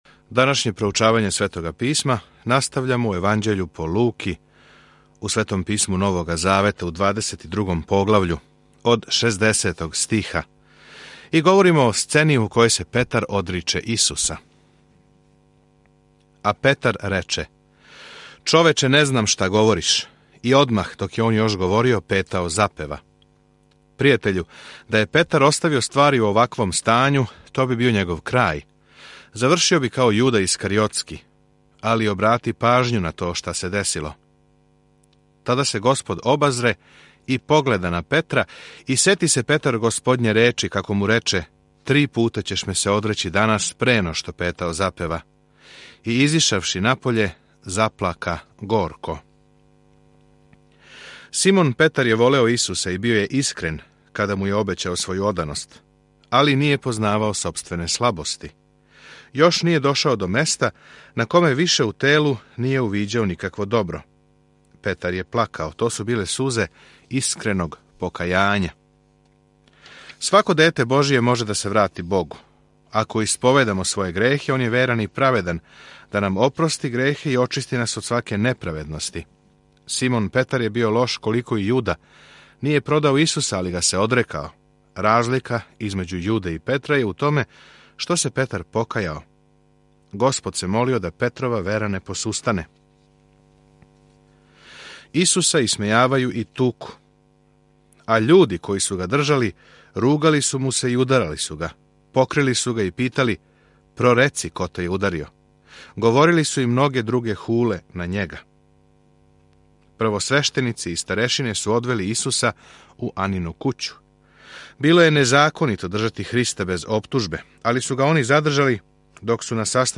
Свакодневно путујте кроз Луку док слушате аудио студију и читате одабране стихове из Божје речи.